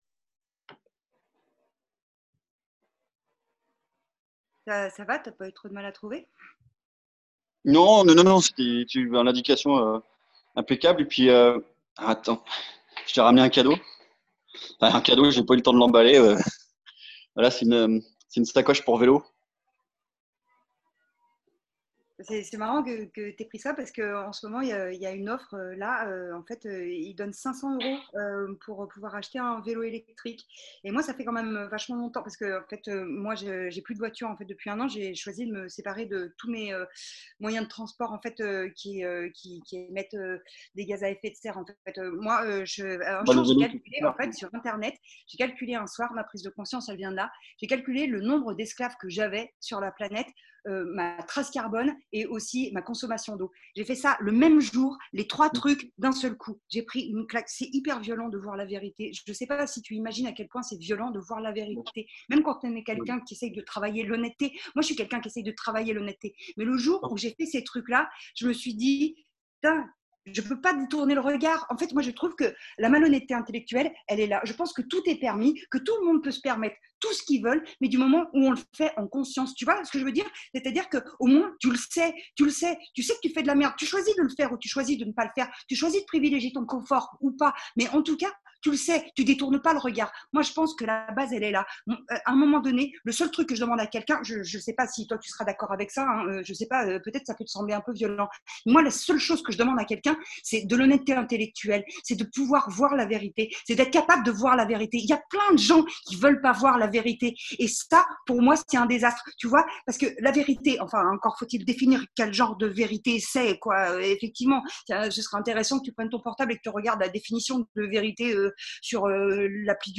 Des fragments de "théâtre improvisé" immatériels, basés sur les relations textuelles.